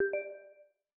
Simple Cute Alert 21.wav